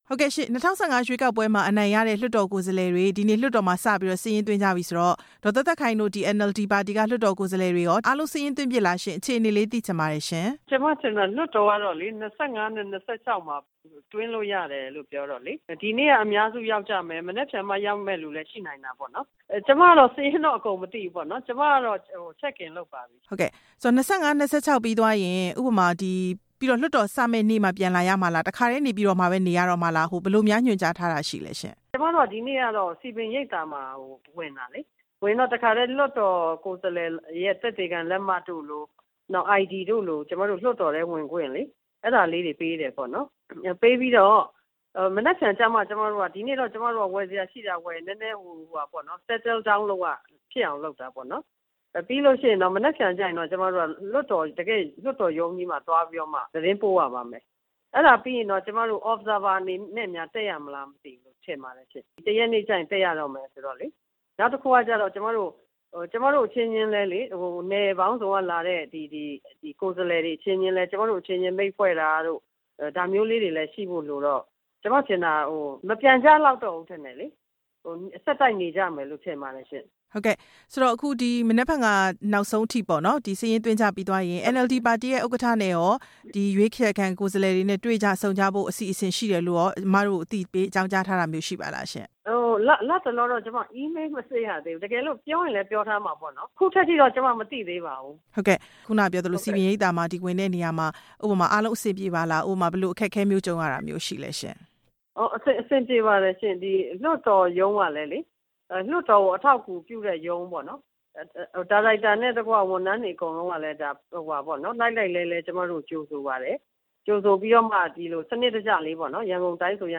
လွှတ်တော် ကိုယ်စားလှယ် ဒေါ်သက်သက်ခိုင်နဲ့ မေးမြန်းချက်